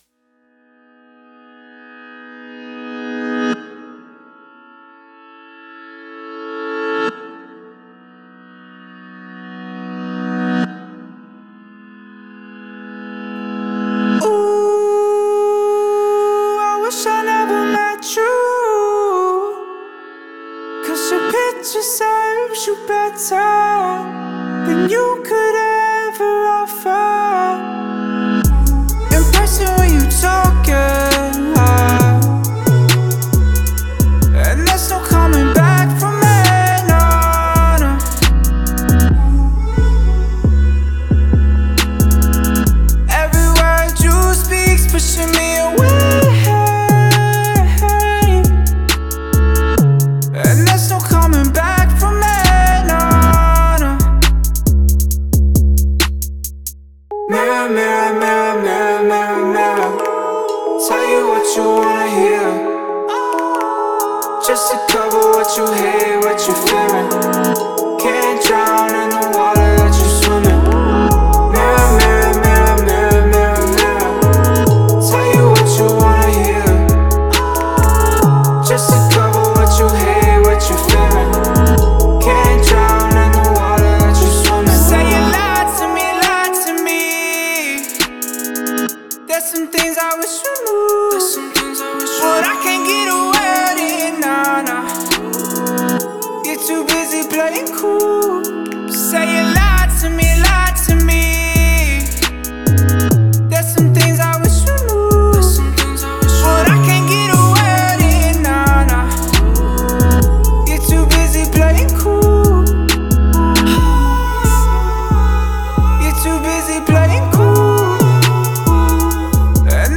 в жанре поп и R&B